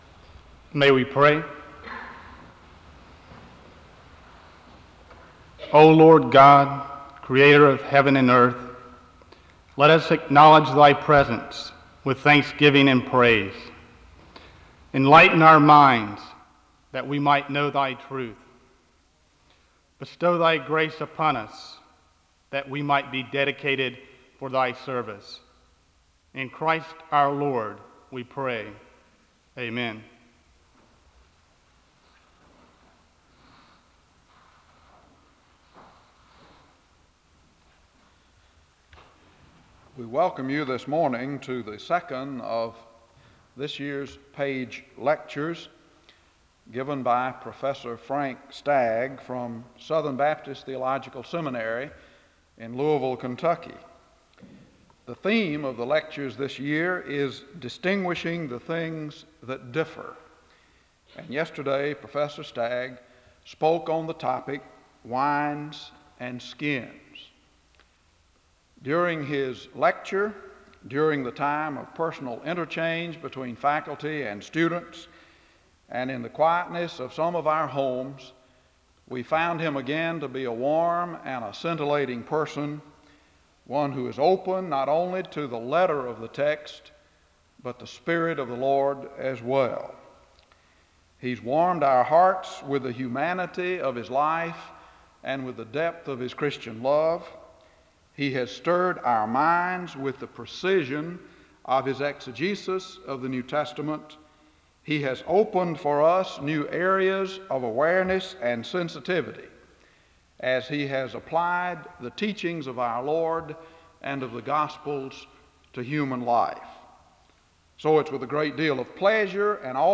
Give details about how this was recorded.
The service opens with a word of prayer (00:00-00:30). The service ends with a word of prayer (49:31-50:17). Location Wake Forest (N.C.)